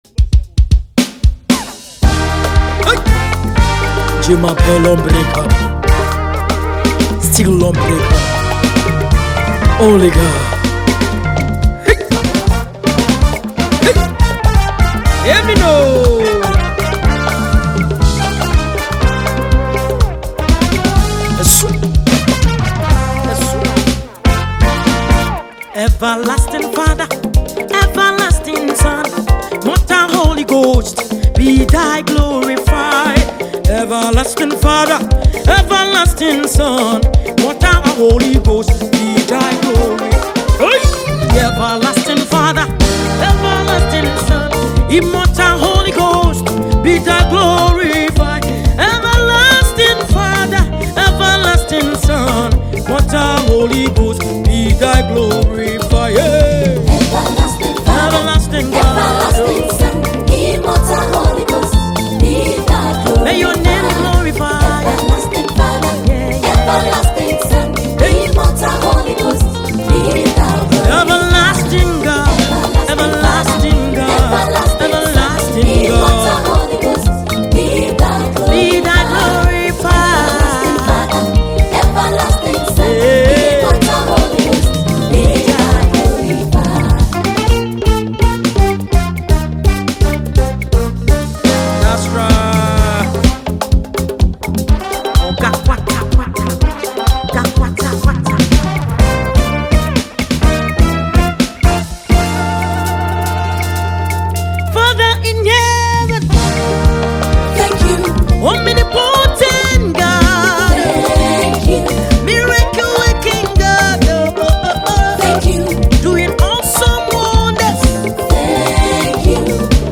Ghanaian award-winning Gospel singer